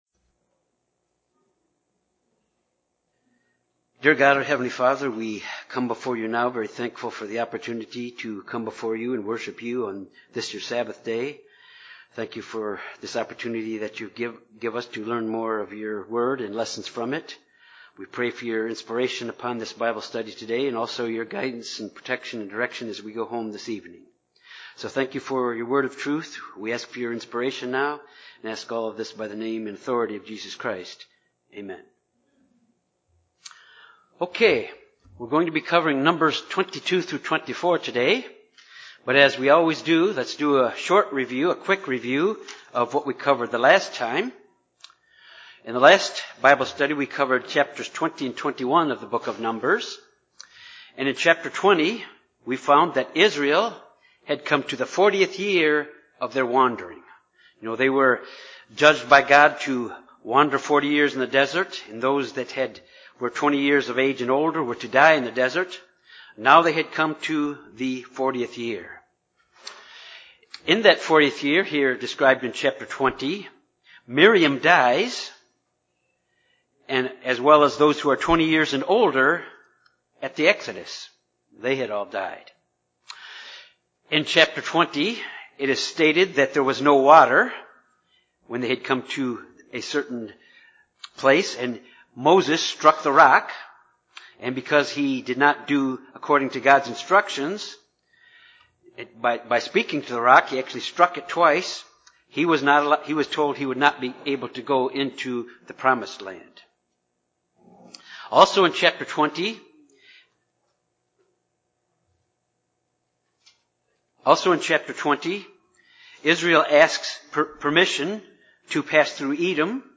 This Bible study covers the incident with Balaam and his talking donkey. Balak, the Baal worshipping king of Moab who feared the approaching Israelites, sent for the prophet Balaam to come and curse the Israelites for him.